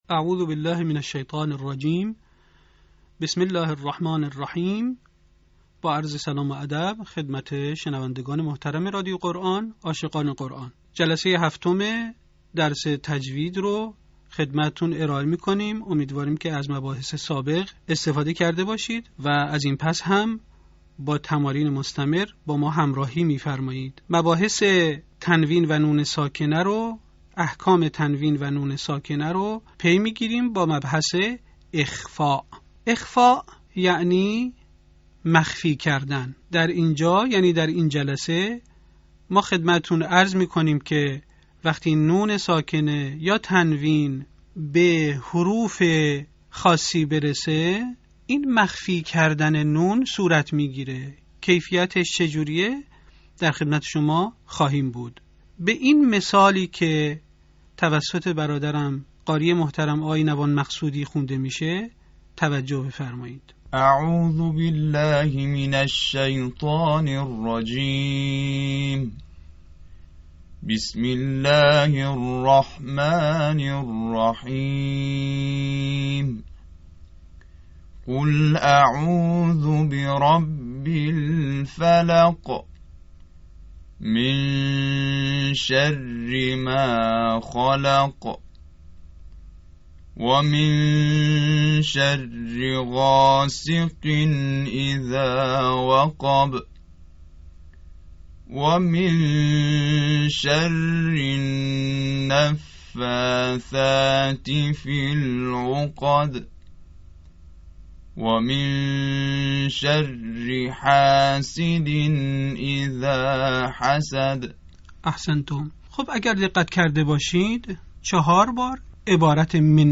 صوت | آموزش «اخفاء» در علم تجوید
به همین منظور مجموعه آموزشی شنیداری (صوتی) قرآنی را گردآوری و برای علاقه‌مندان بازنشر می‌کند.